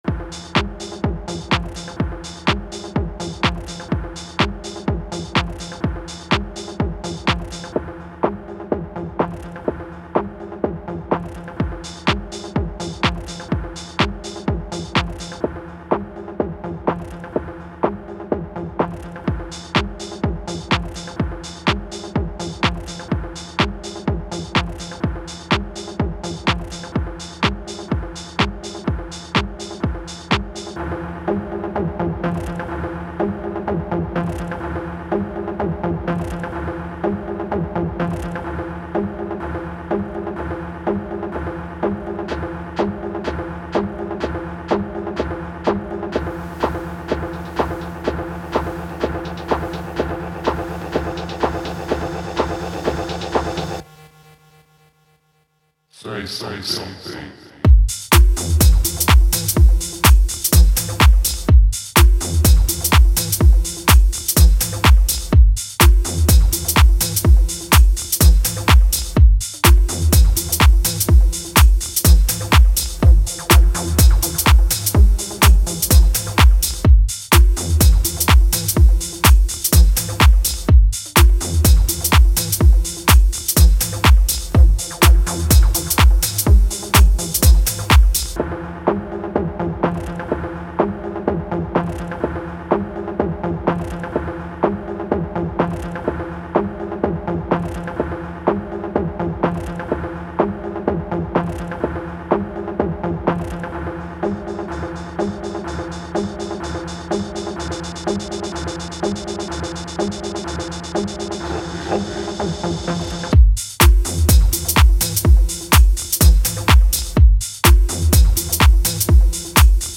Tech House
Deep Tech sounds